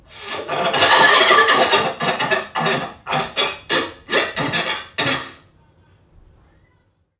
NPC_Creatures_Vocalisations_Robothead [55].wav